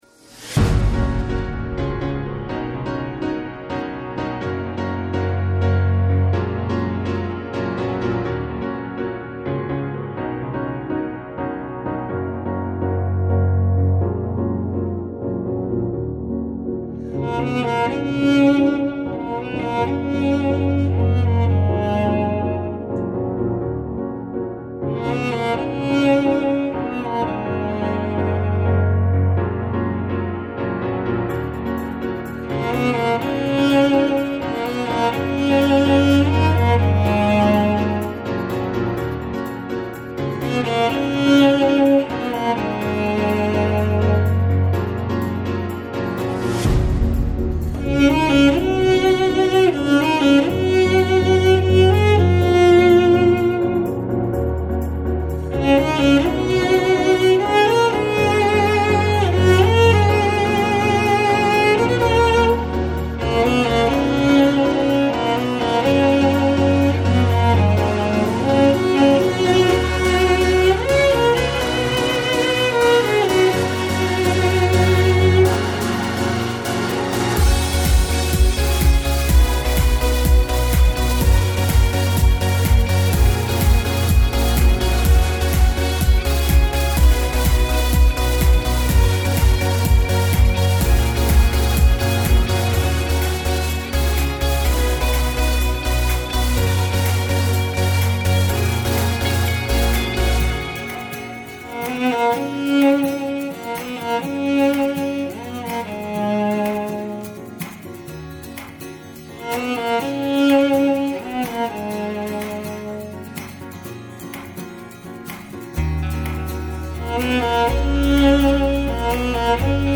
Female Solo Cellist